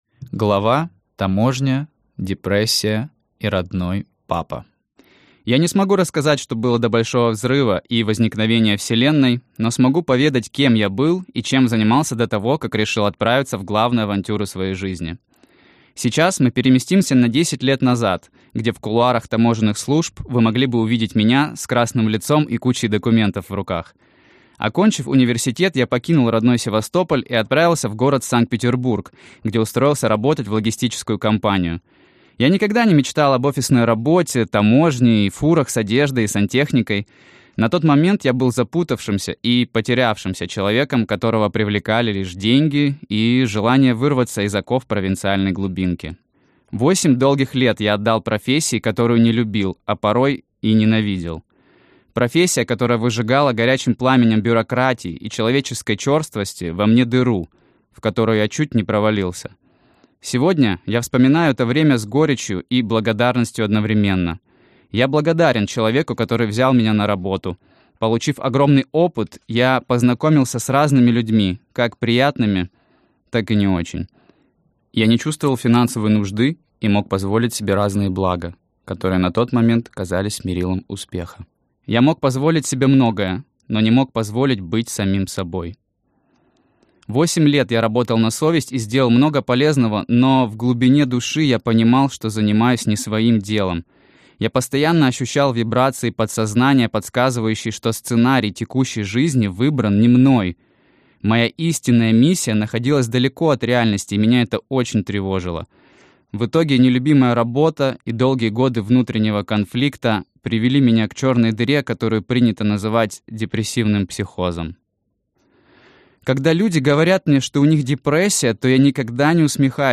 Аудиокнига 537 дней без страховки. Как я бросил все и уехал колесить по миру | Библиотека аудиокниг